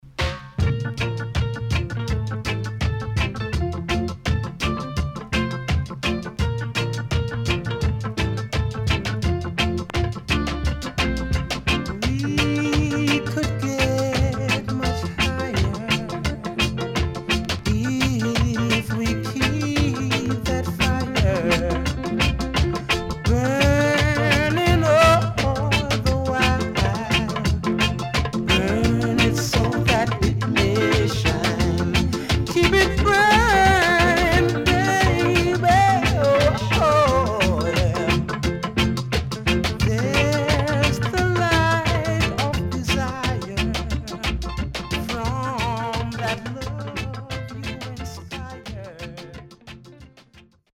HOME > Back Order [VINTAGE 7inch]  >  EARLY REGGAE
SIDE A:少しチリノイズ入りますが良好です。